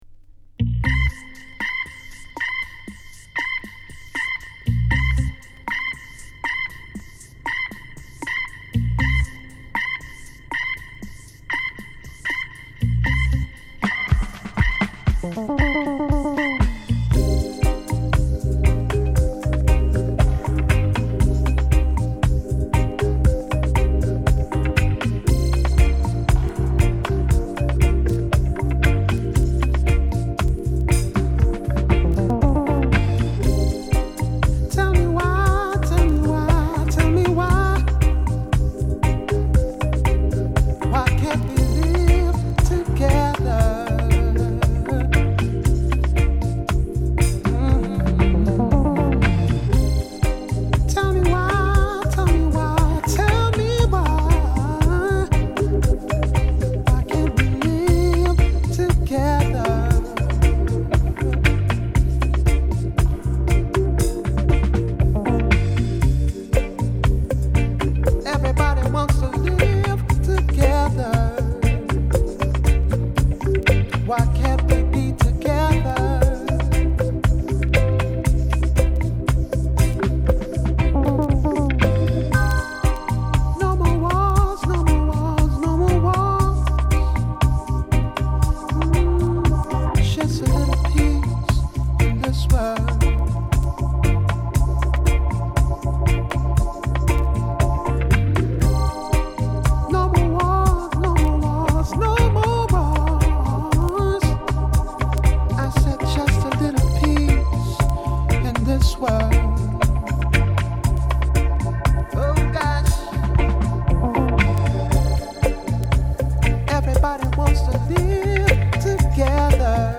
ソウルフルなレゲエ・カヴァーを披露
Discomix Version